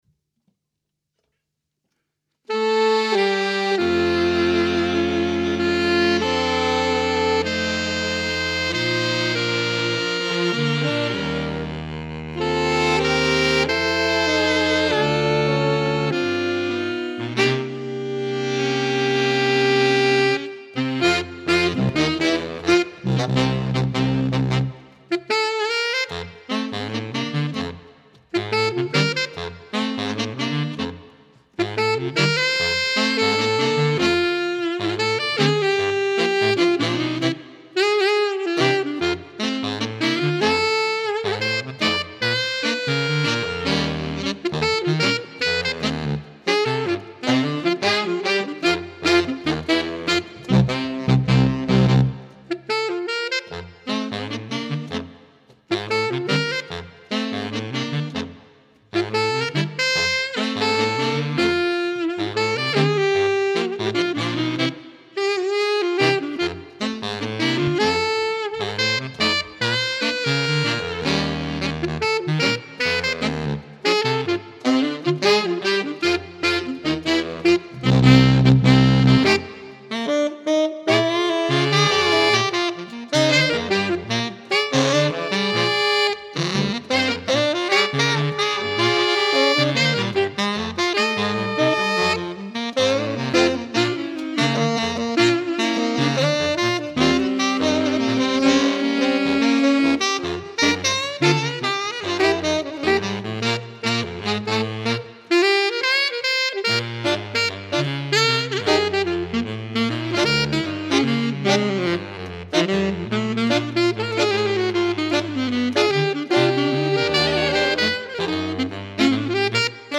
Ranges: Alto 1: D#3. Tenor: Eb3. Baritone: A1 with ossias